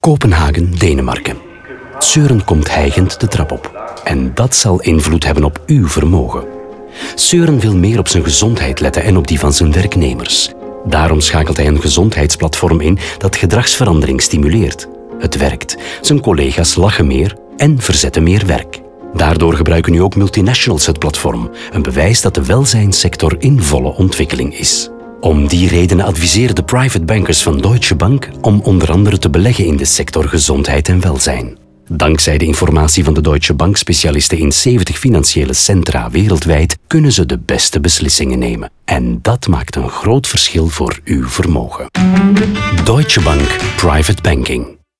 In de radiospots krijgen de verhalen van Rinku, Søren, Juan en Edward ruim de tijd, 45 seconden, om helemaal verteld te worden.